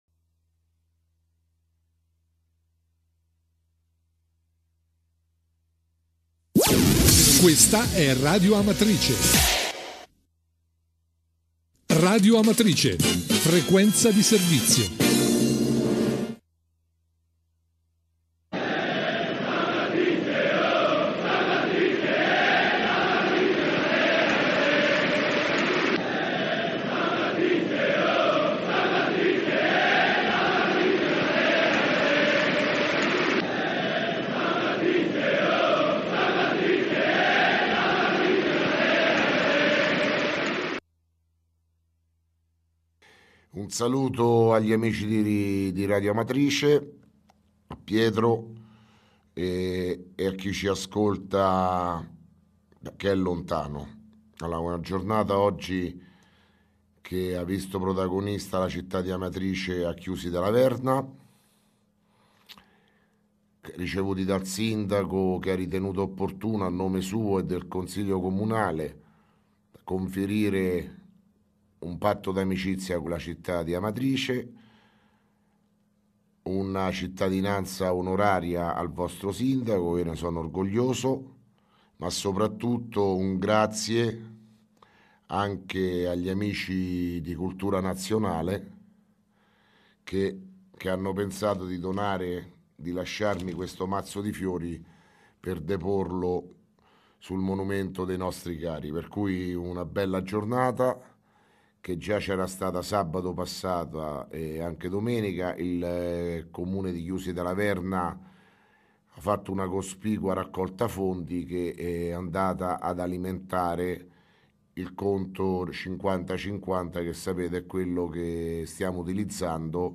Di seguito il messaggio audio del Sindaco Sergio Pirozzi dell’ 12 marzo 2017